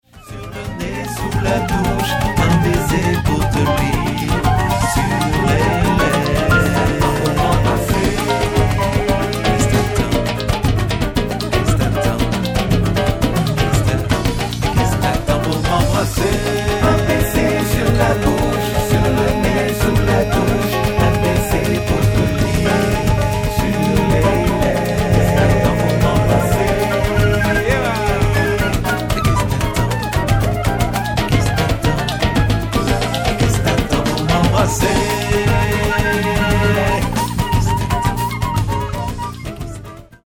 快活なボサジャズの